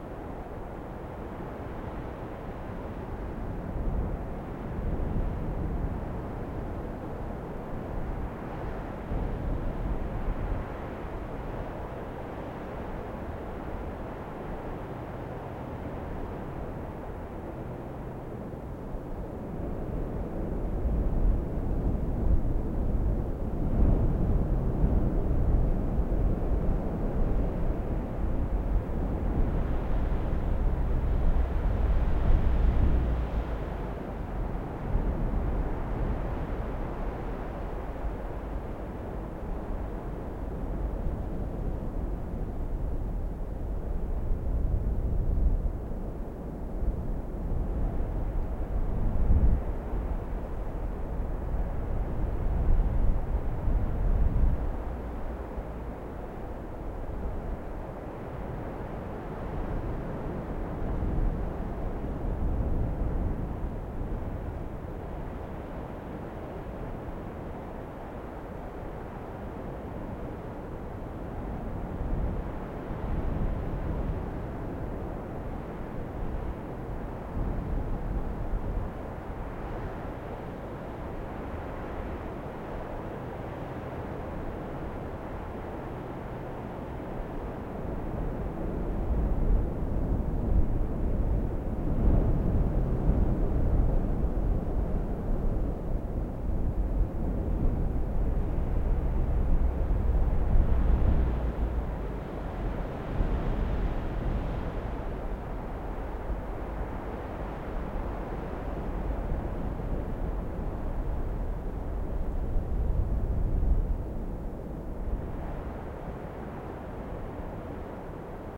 windLightLoop.ogg